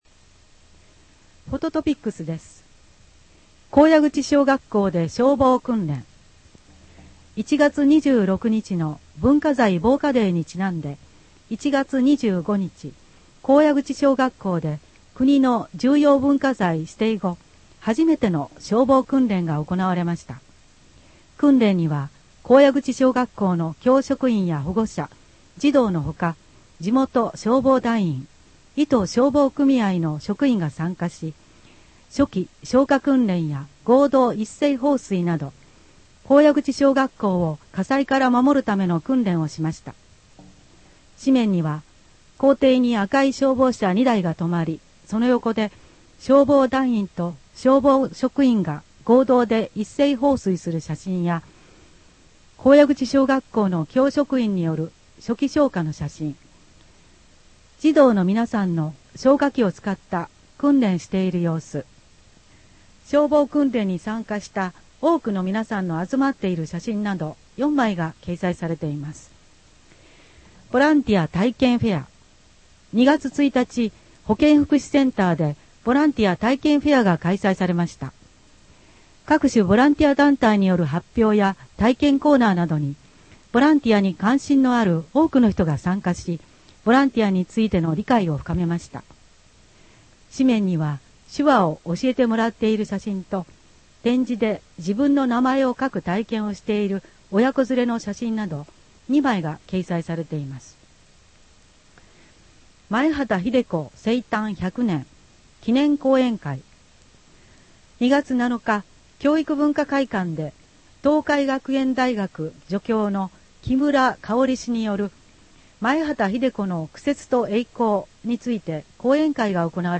WEB版　声の広報 2015年3月号